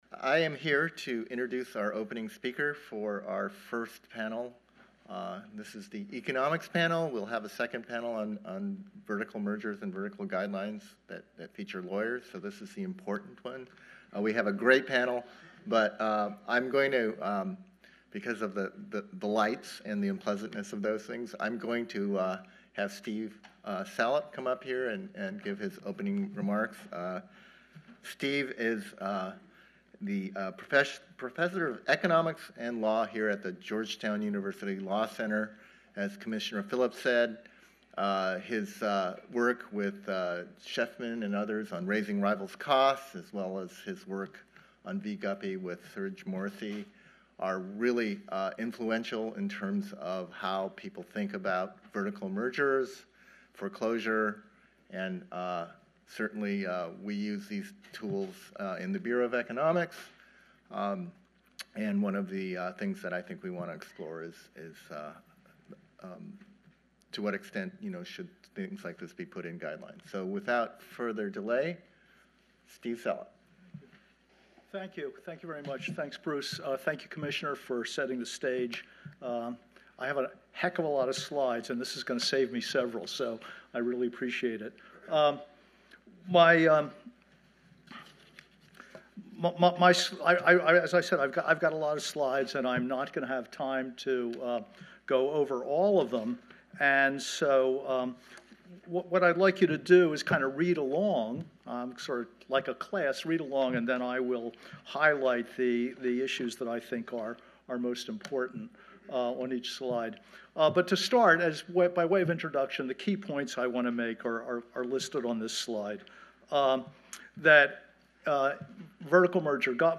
Vertical Mergers Presentation (Presentation)